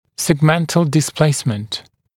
[segˈmentl dɪs’pleɪsmənt][сэгˈмэнтл дис’плэйсмэнт]сегментарное перемещение